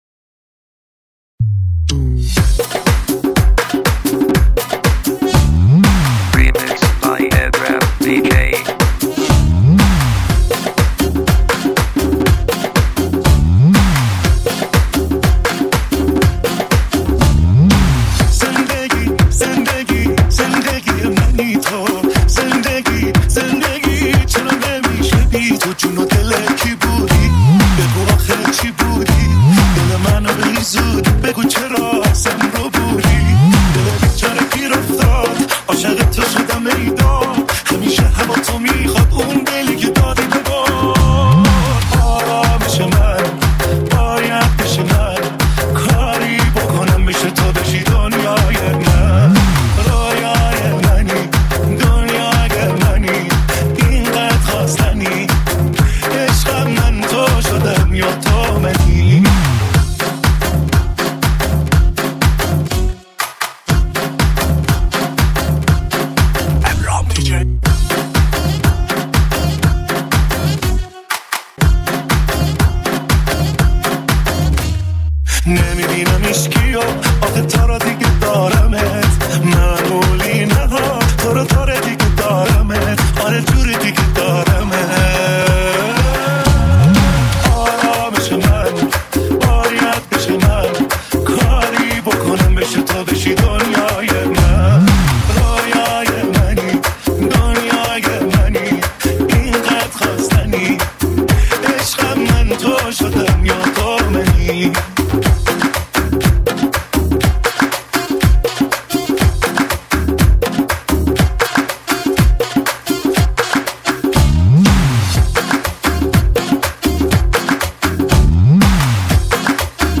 دانلود ریمیکس شاد جدید